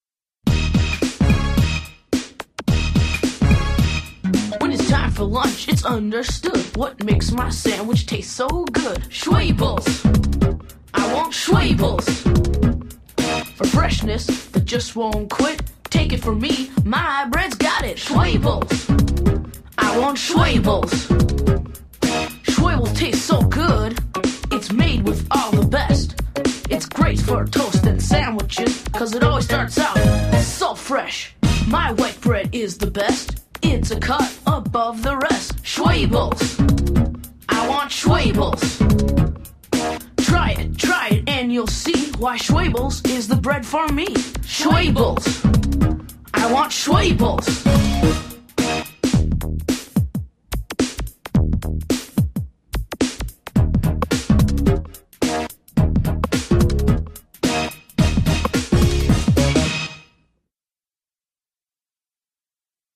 radio advertisement